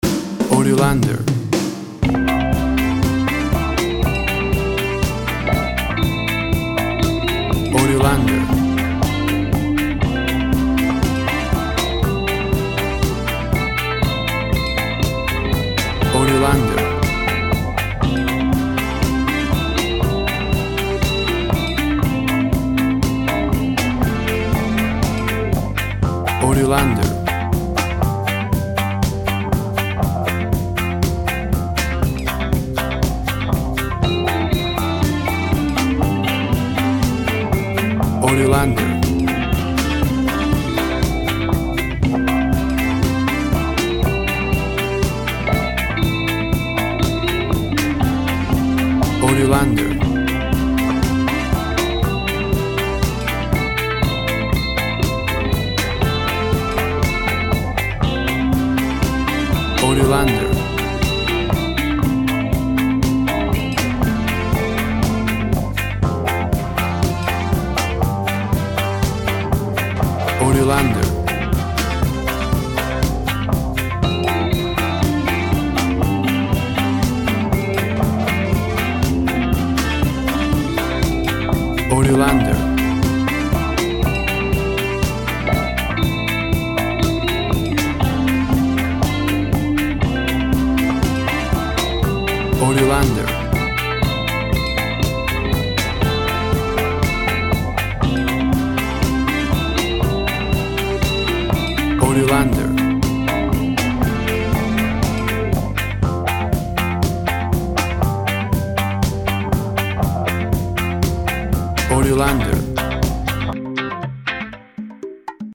Joyful peace of reggae, party beach.
WAV Sample Rate 16-Bit Stereo, 44.1 kHz
Tempo (BPM) 120